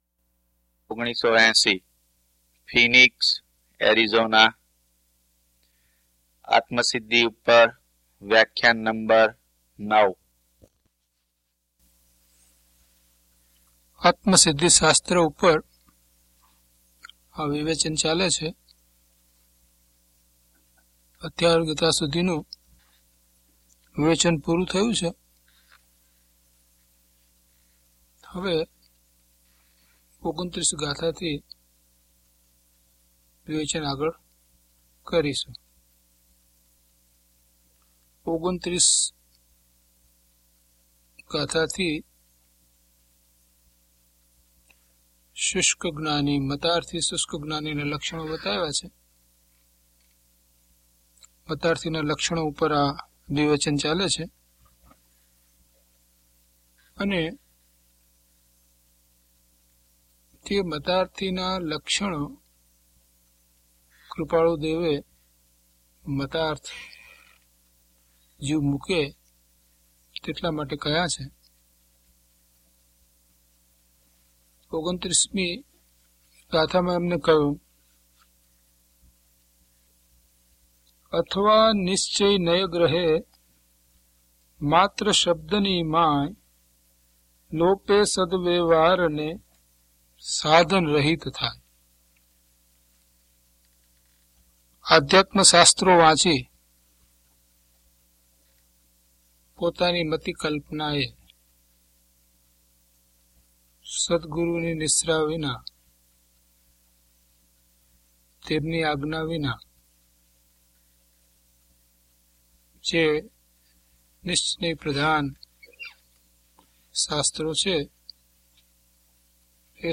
DHP018 Atmasiddhi Vivechan 9 - Pravachan.mp3